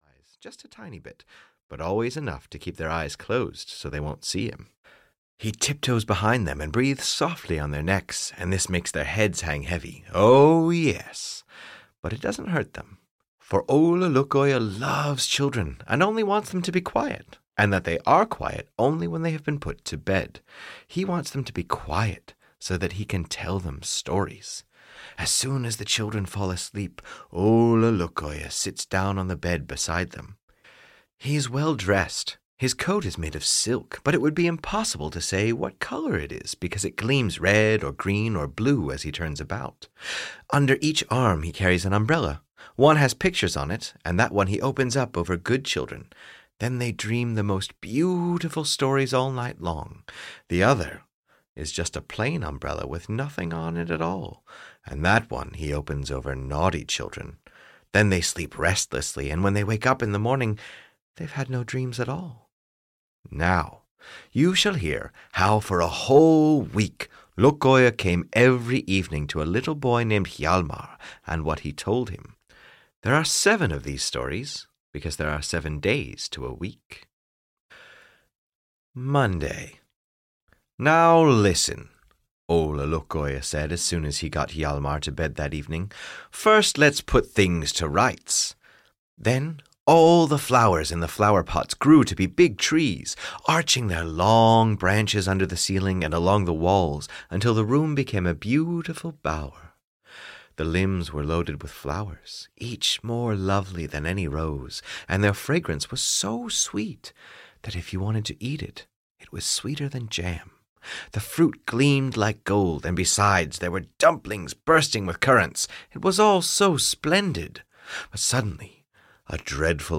Ole Lukoie (EN) audiokniha
Ukázka z knihy